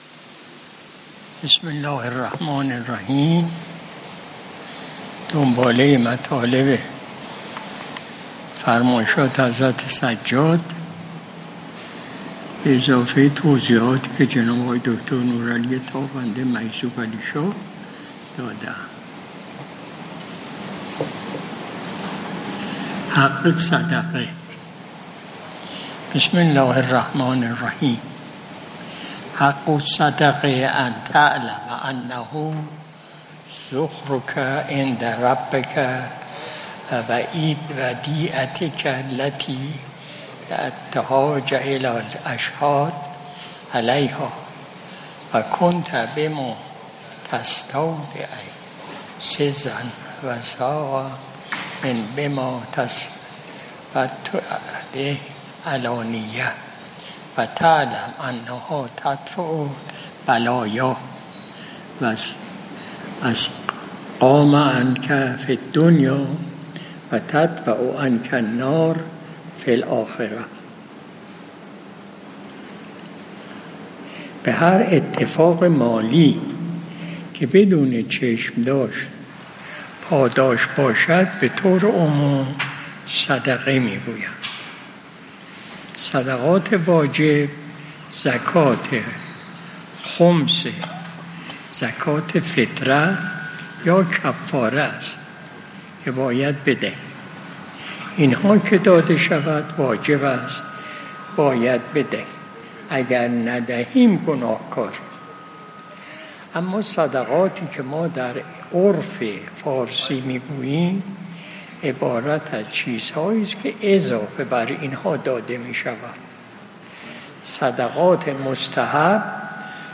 مجلس شب جمعه ۵ مرداد ماه ۱۴۰۲ شمسی